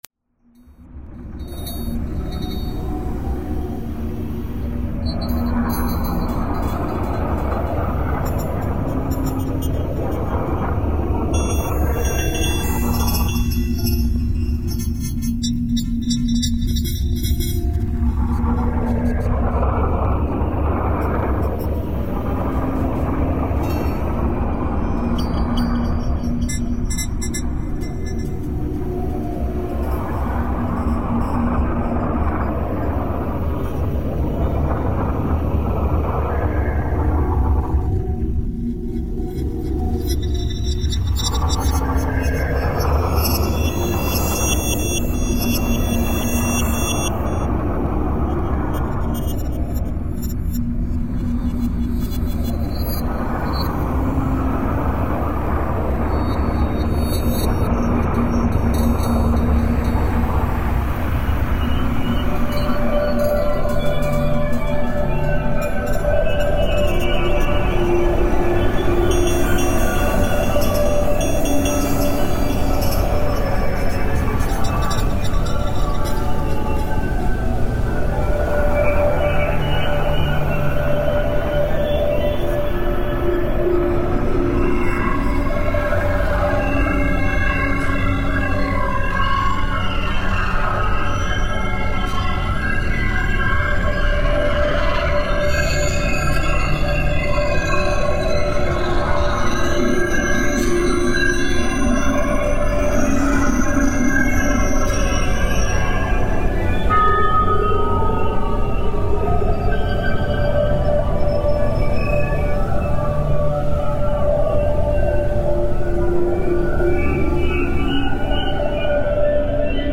File under: Avantgarde / Experimental
solo electronic music project